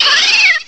Add all new cries
cry_not_skiddo.aif